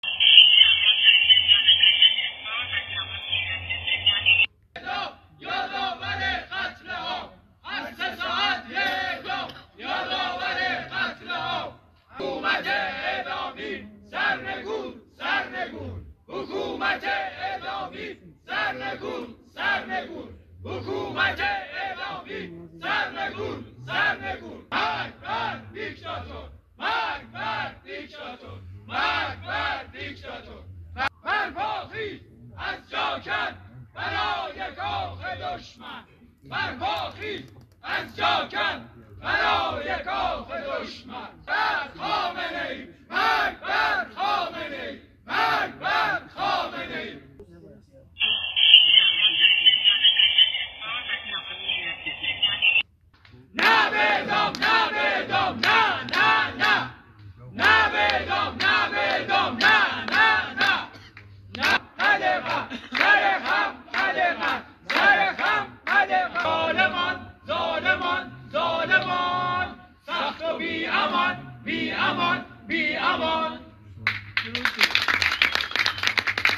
طنین شعار زندانیان سیاسی زندان قزلحصار
به‌مناسبت روز جهانی علیه مجازات اعدام